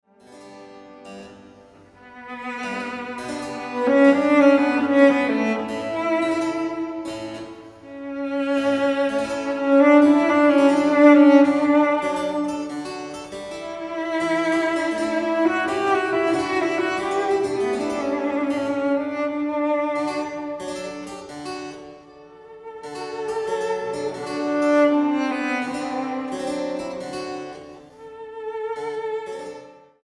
Sonata VI en Sol Mayor